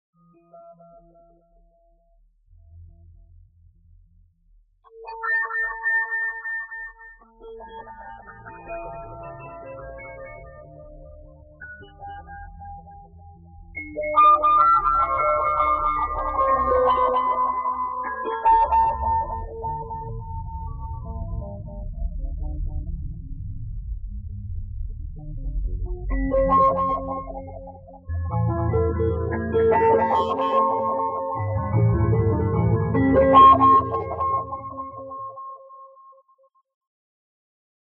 watersound-kw_flt1.wav